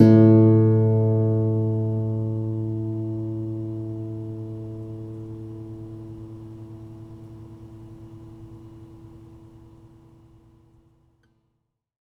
R - Foley 4.wav